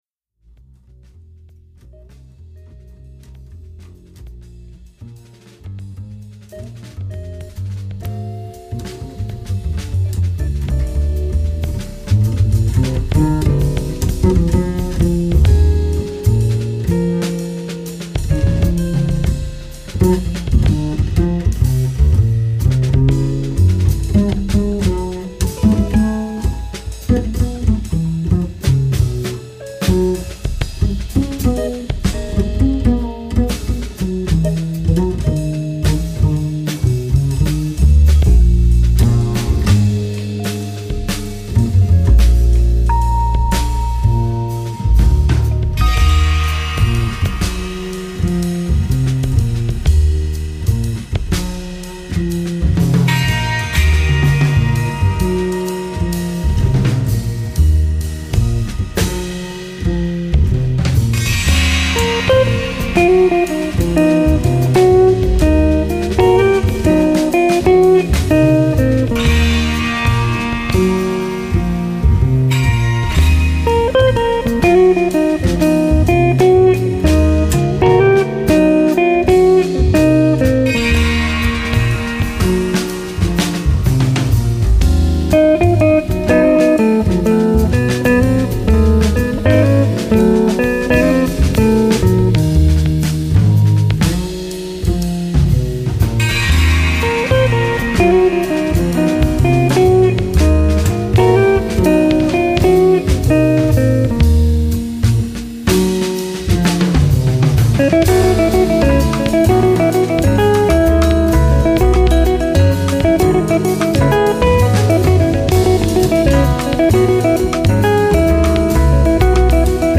Electric and Acoustic Guitars
Keyboards
Electric and Acoustic Bass
Drums